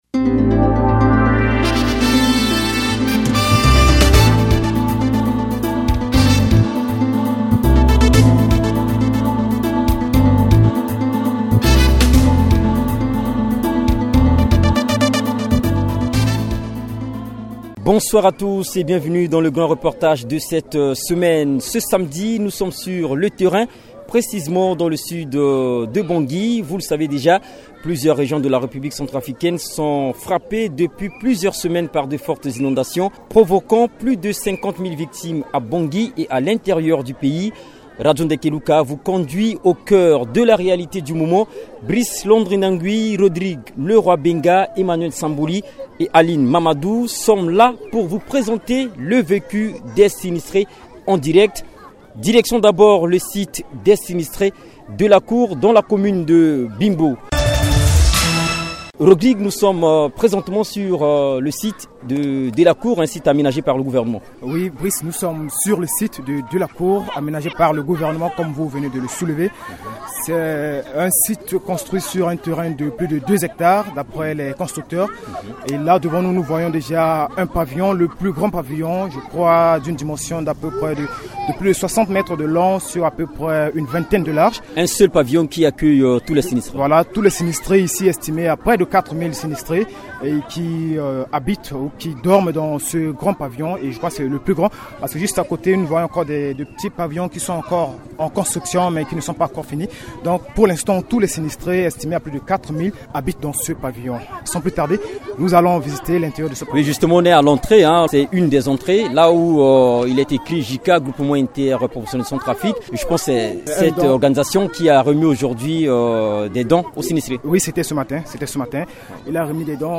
vous proposent un reportage.